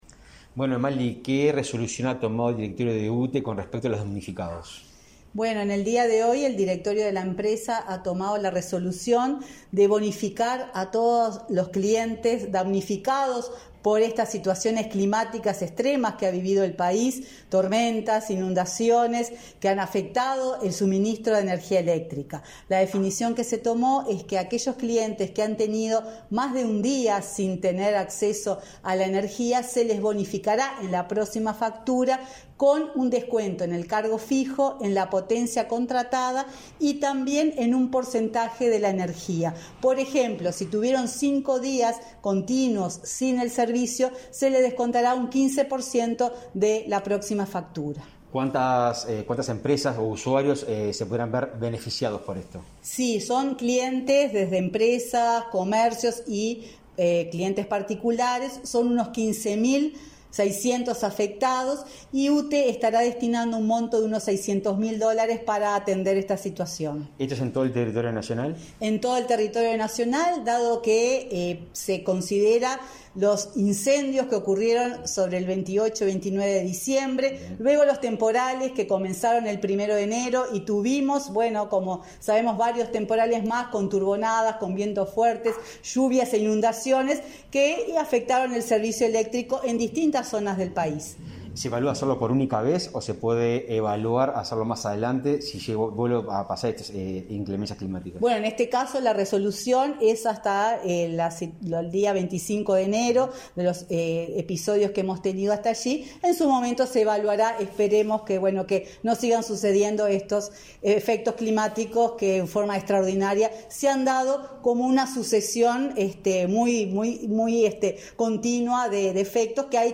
Entrevista a la presidenta de UTE, Silvia Emaldi
La titular del organismo, en entrevista con Comunicación Presidencial, explicó la medida.